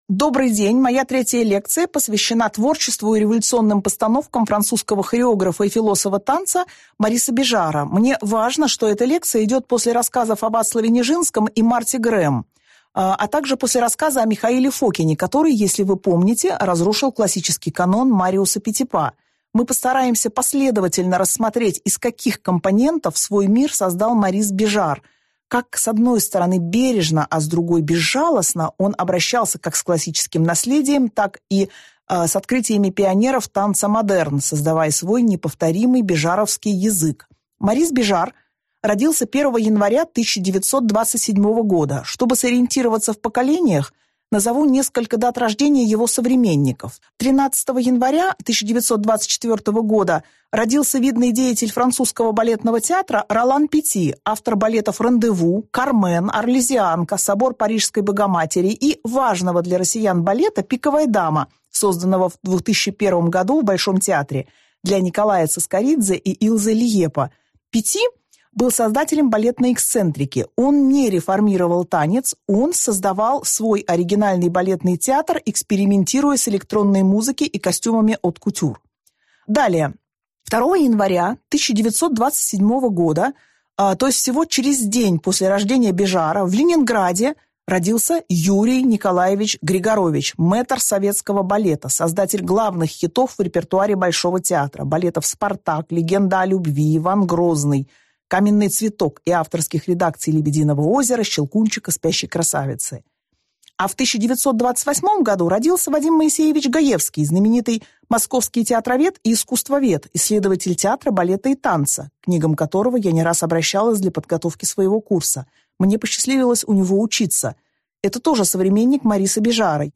Аудиокнига Морис Бежар – крупнейший игрок на театральной сцене XX века и лидер мирового фестивального движения | Библиотека аудиокниг